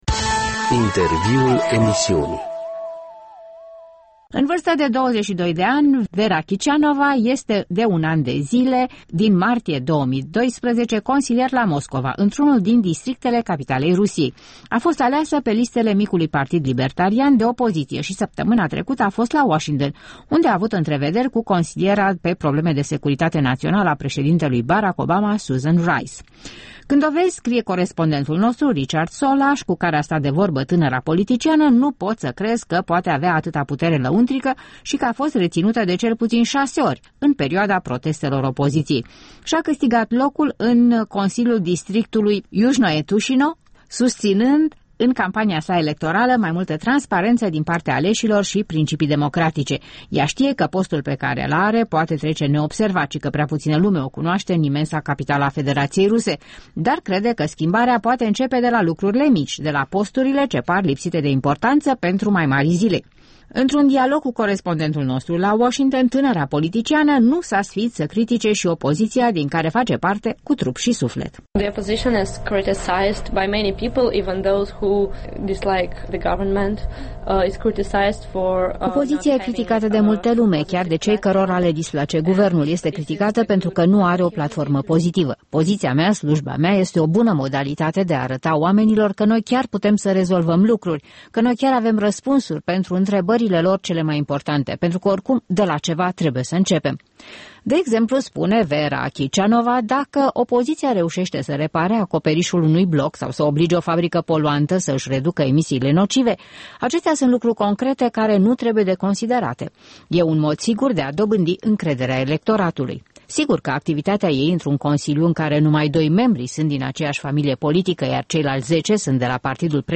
Embed Un interviu cu o membră a opoziției din Rusia